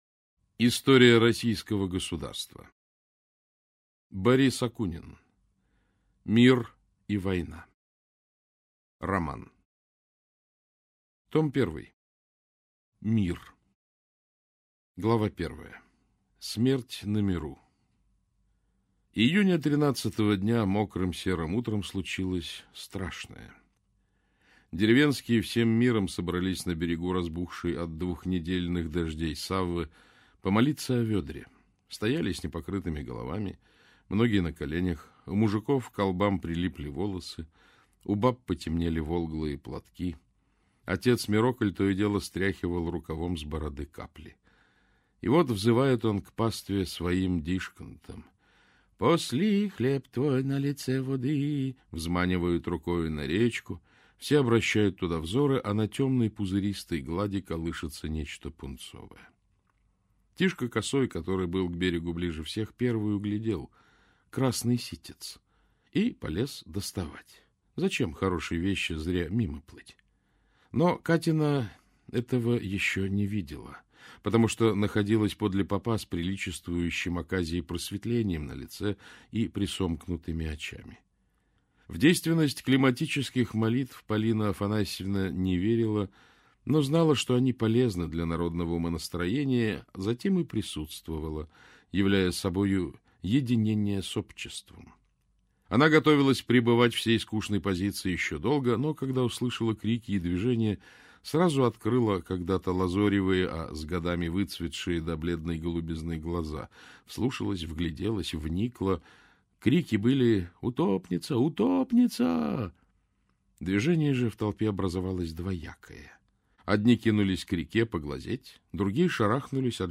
Аудиокнига Мир и война - купить, скачать и слушать онлайн | КнигоПоиск